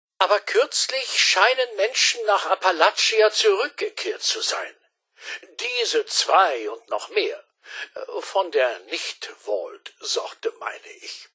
Fallout 76: Audiodialoge
Beschreibung Lizenz Diese Datei wurde in dem Video-Spiel Fallout 76 aufgenommen oder stammt von Webseiten, die erstellt und im Besitz von Bethesda Softworks sind, deren Urheberrecht von Bethesda Softworks beansprucht wird.